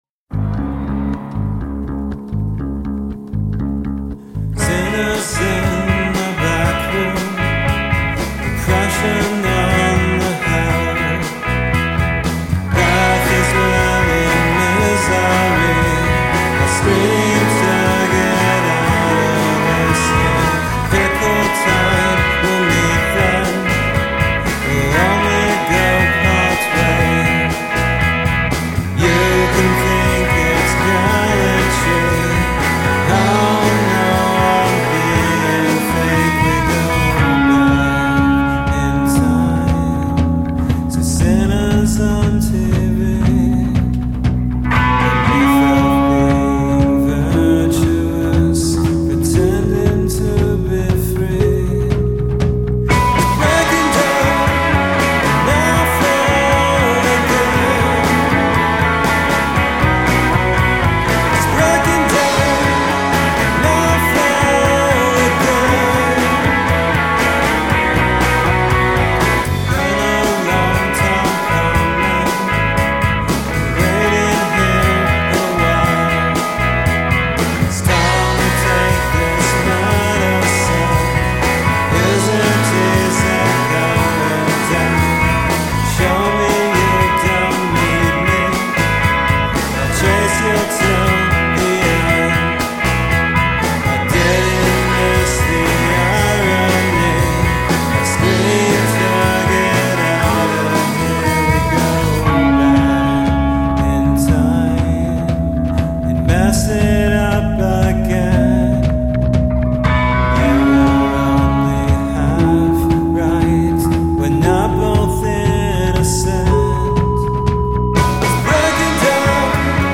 4 piece rock band
vocals, guitar
guitar, keys
bass, keys
drums, percussion